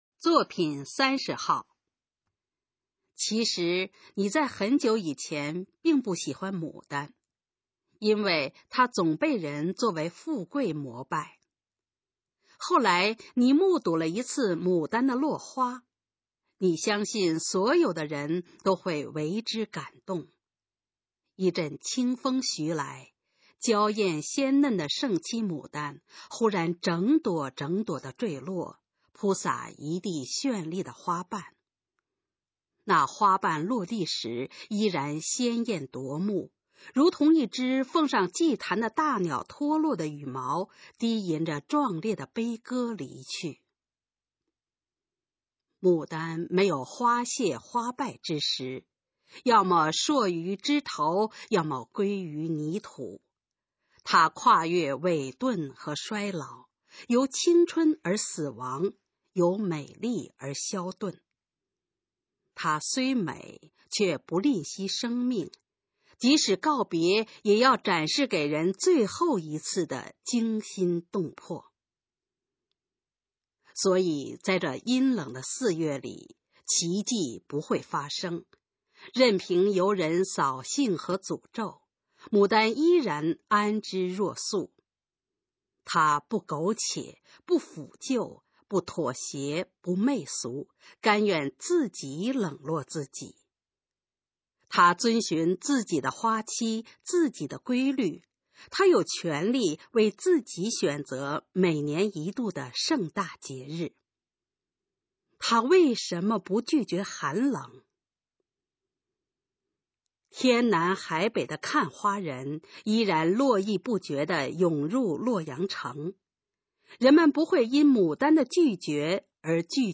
《牡丹的拒绝》示范朗读_水平测试（等级考试）用60篇朗读作品范读　/ 佚名